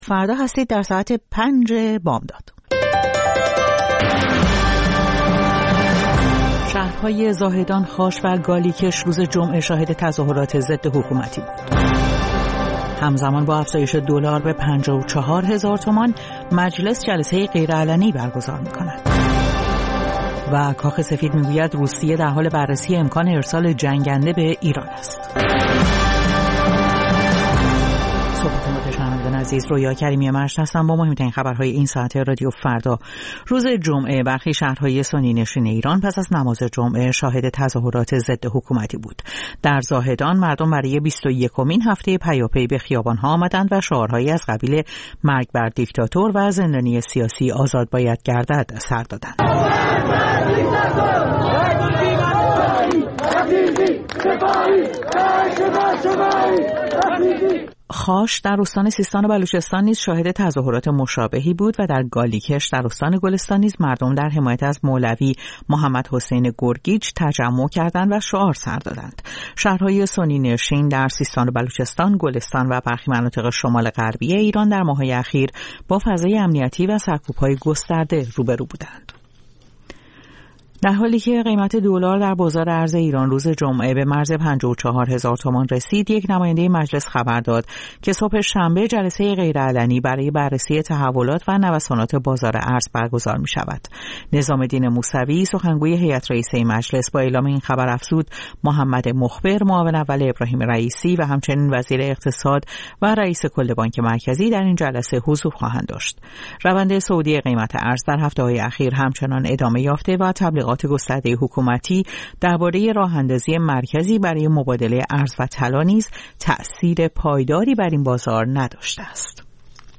سرخط خبرها ۵:۰۰